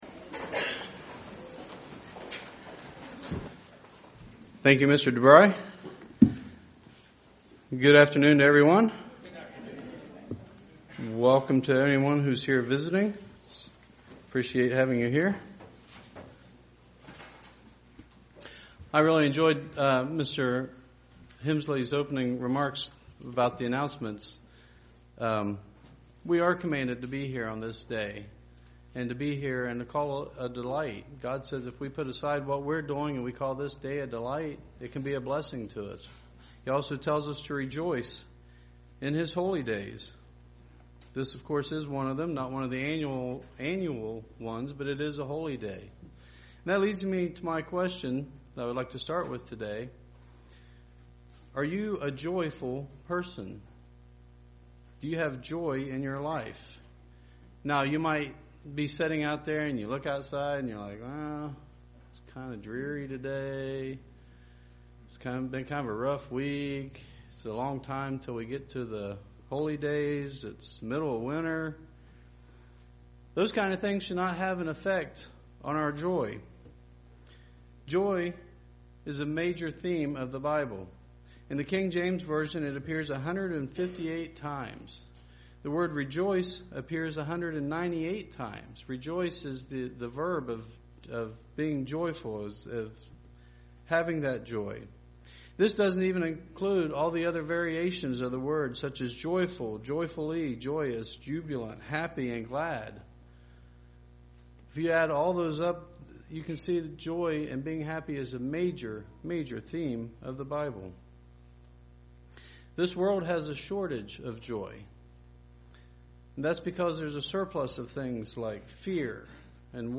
How do we develop joy in our lives? This sermon points out 5 things that will help us develop joy in our lives!